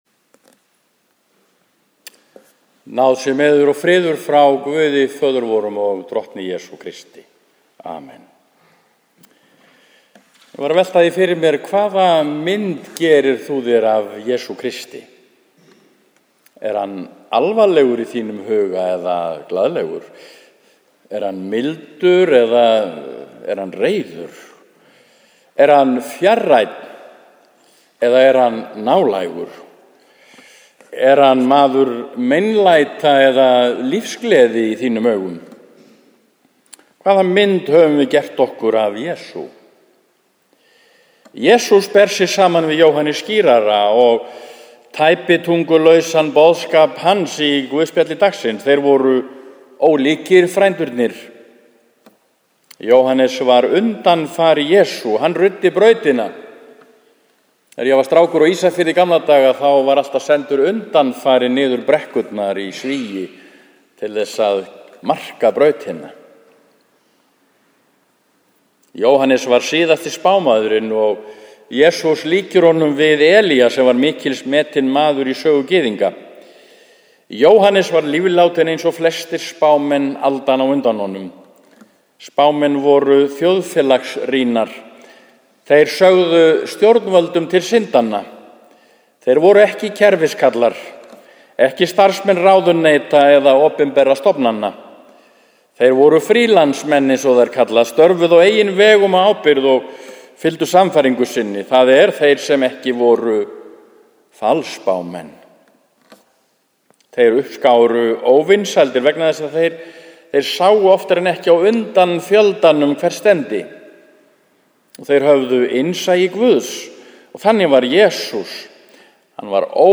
Prédikun í Neskirkju Verslunarmannahelgina